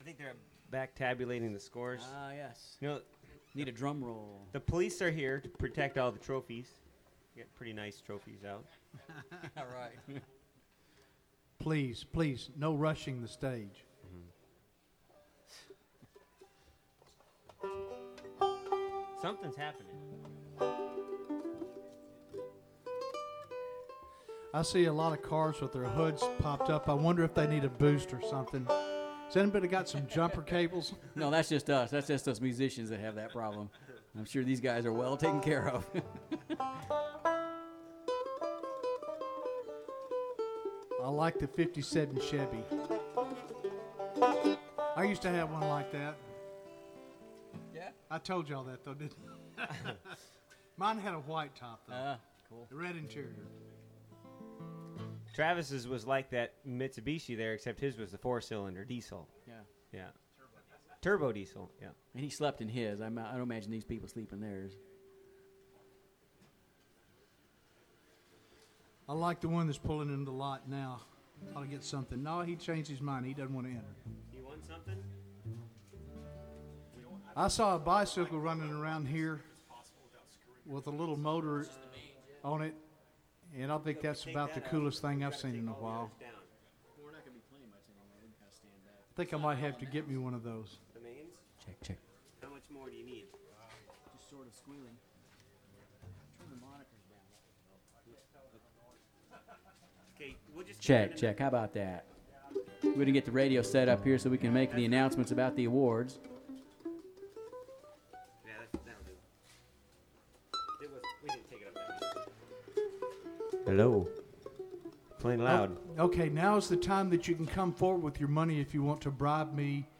Click Here for the Car Show awards ceremony recorded live on KKNI-FM. Rough but lots of fun.